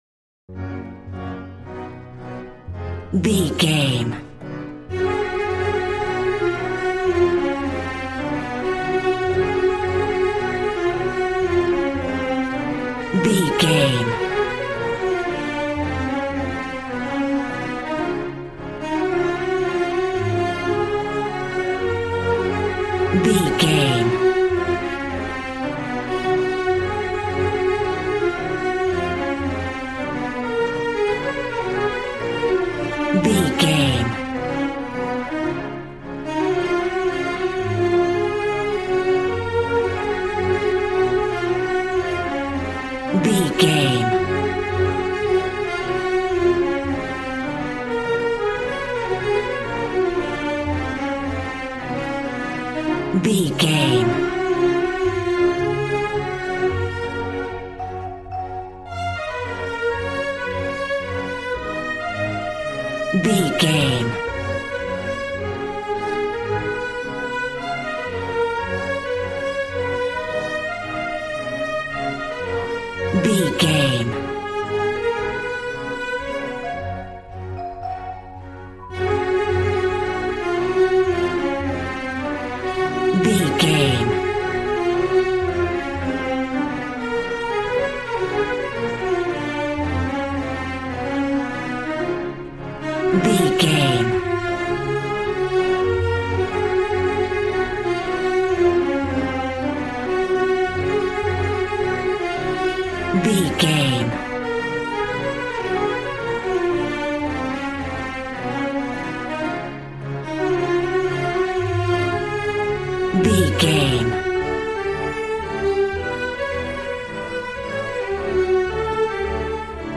Ionian/Major
G♭
cheerful/happy
joyful
drums
acoustic guitar